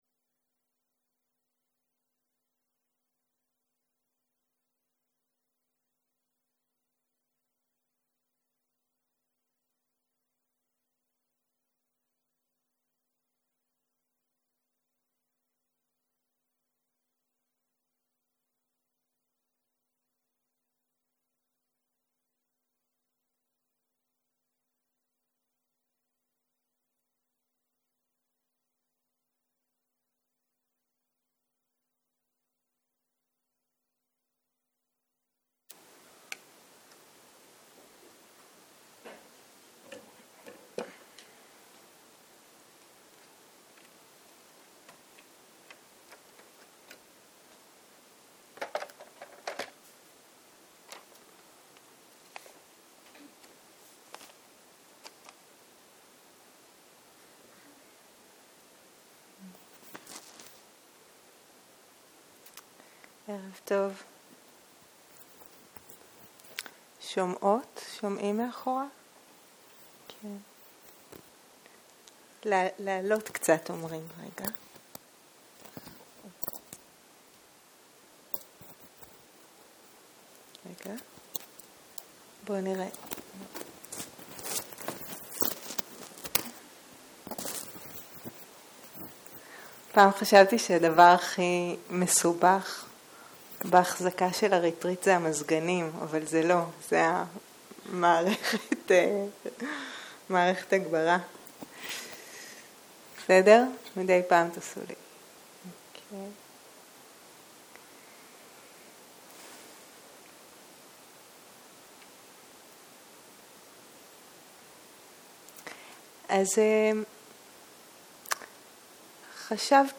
ערב - שיחת דהרמה